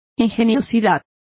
Complete with pronunciation of the translation of ingenuity.